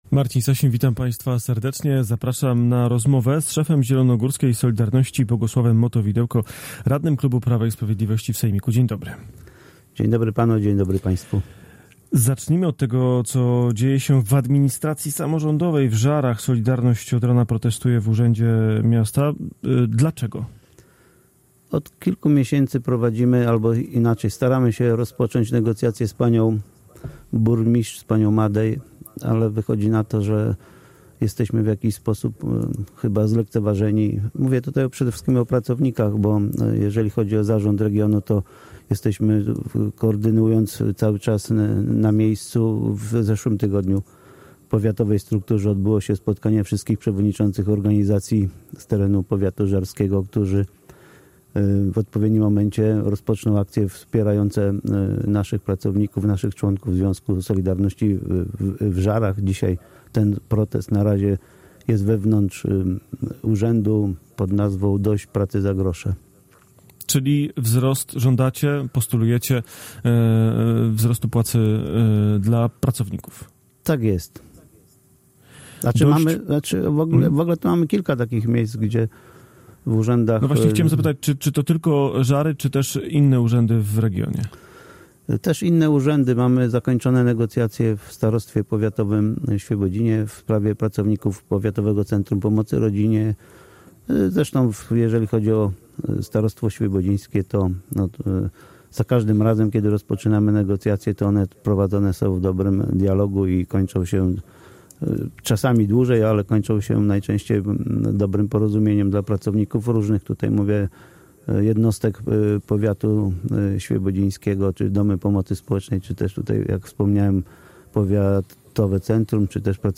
Z przewodniczącym zielonogórskiej „Solidarności”, radnym klubu PiS w sejmiku rozmawia